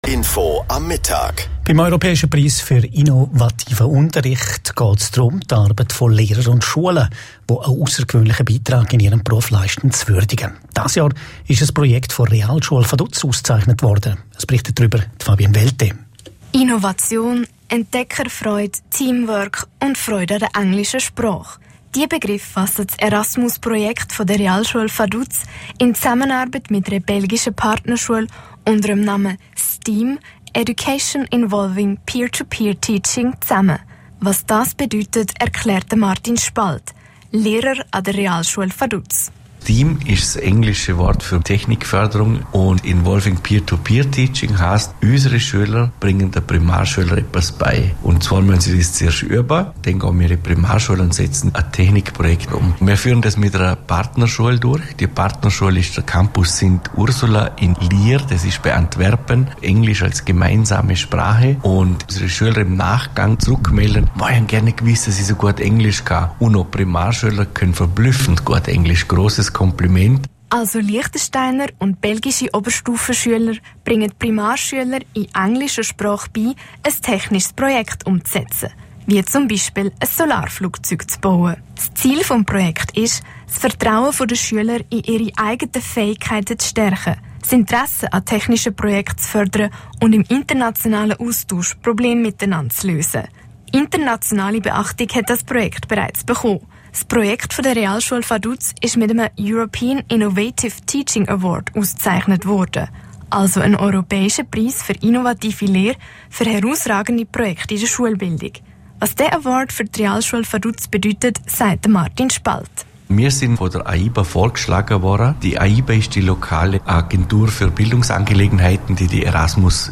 Beitrag auf Radio L